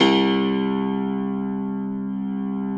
53q-pno02-C0.wav